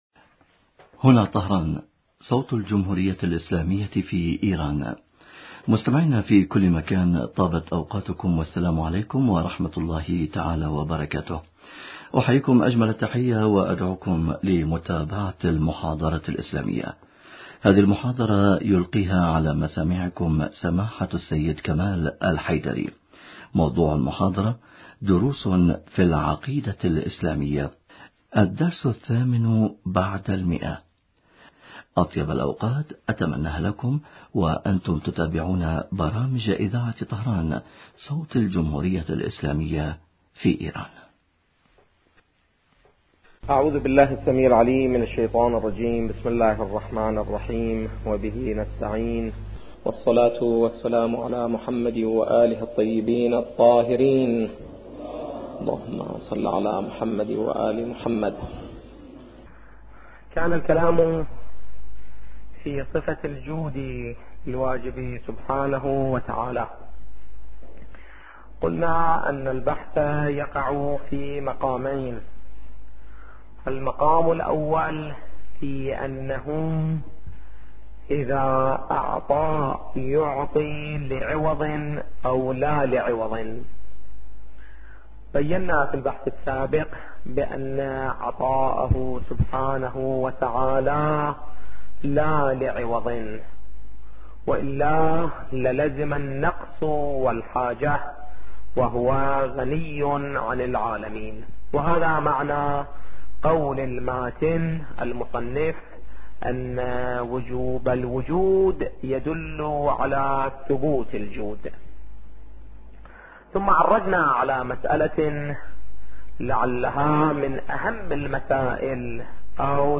دروس في العقيدة الاسلامية - الدرس الثامن بعد المئة